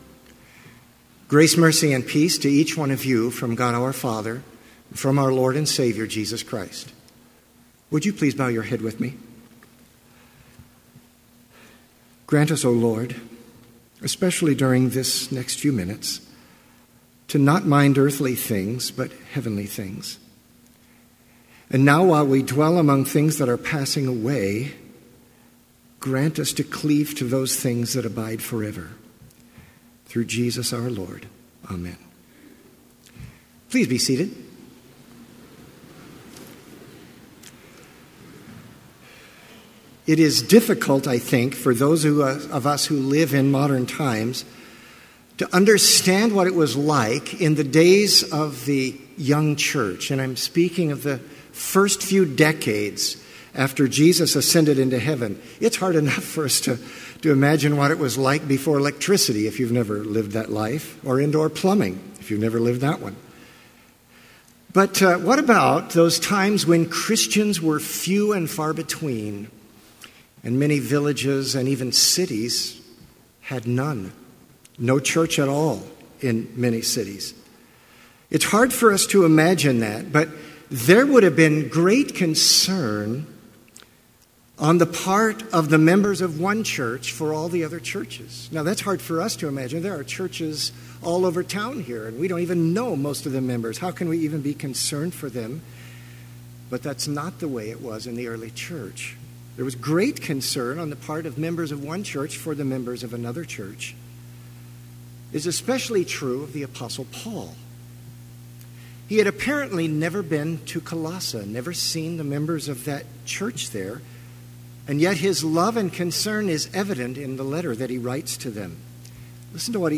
Complete service audio for Chapel - November 15, 2017
Watch Listen Complete Service Audio file: Complete Service Sermon Only Audio file: Sermon Only Order of Service Prelude Hymn 534, vv. 6 & 7, O home of fadeless…
Blessing Postlude Scripture Colossians 3:1-4 If then you were raised with Christ, seek those things which are above, where Christ is, sitting at the right hand of God.